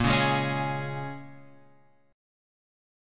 A# Minor 7th
A#m7 chord {6 4 6 6 x 4} chord